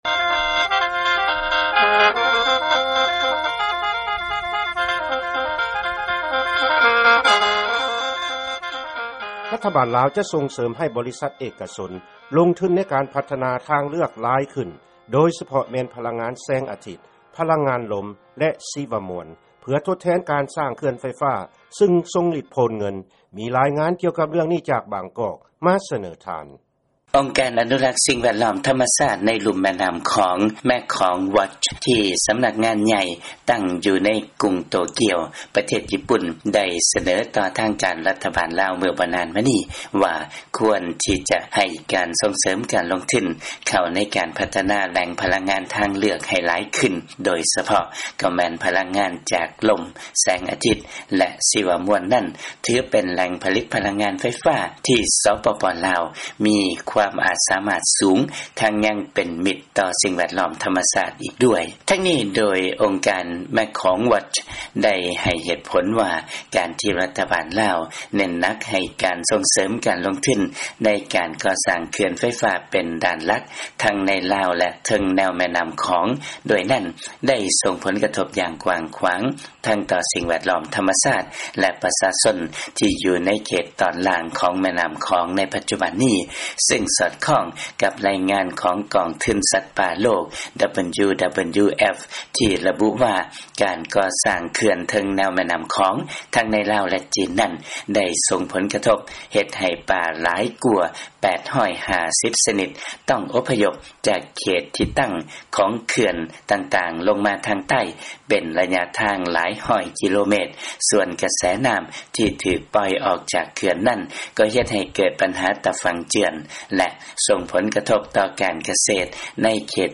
ເຊີນຟັງລາຍງານເລື່ອງການສົ່ງເສີມການພັດທະນາພະລັງງານທາງເລືອກຢູ່ລາວ